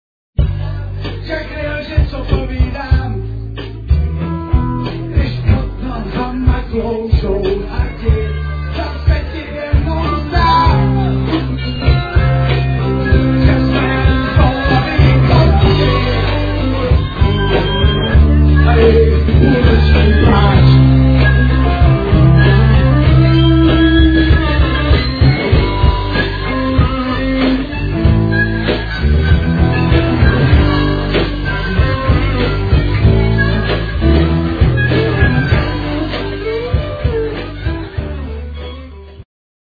saw
Live from Prague [2002].